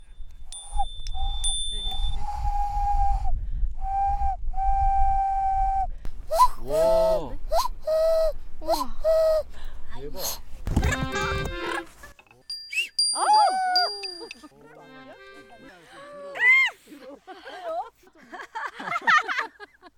뻐꾸기웃음.mp3